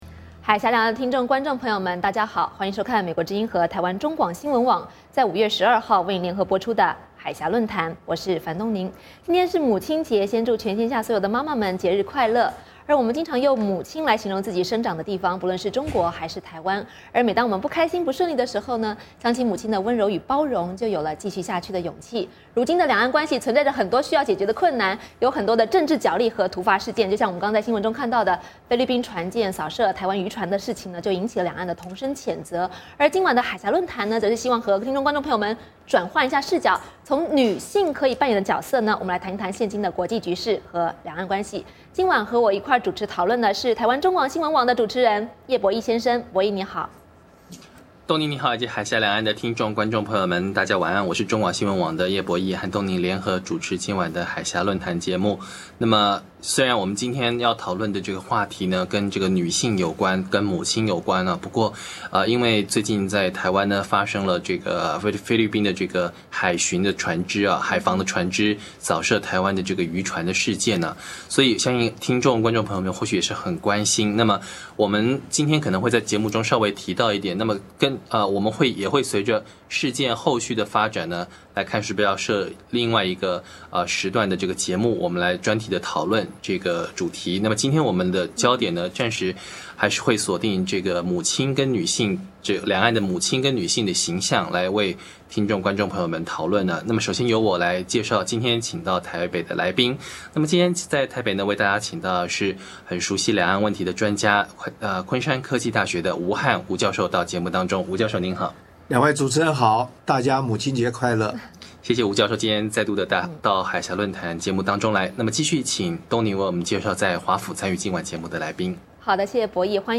今晚海峡论谈播出母亲节特别节目，两位来宾围绕几位不同特质的女性，谈谈她们对两岸关系能够发挥的影响力，上半场谈的是两岸的第一夫人--彭丽媛和周美青。